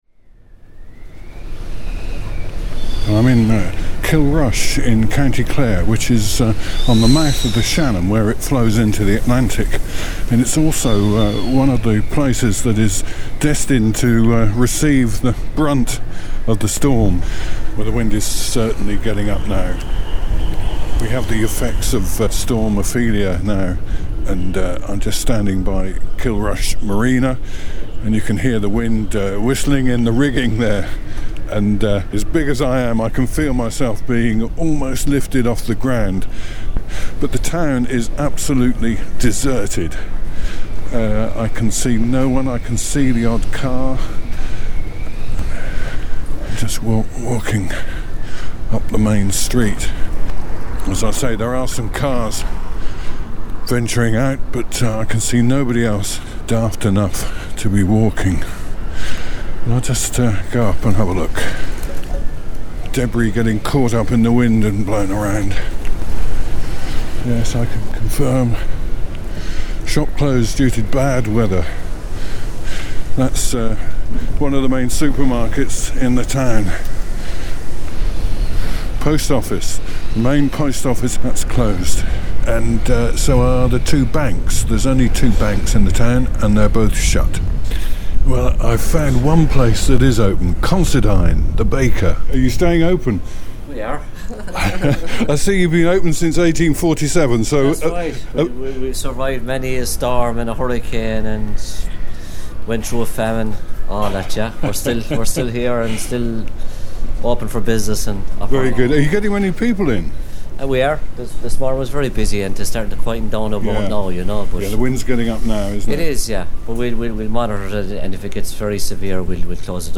reports as Storm Ophelia hits Kilrush on Monday 16th October 2017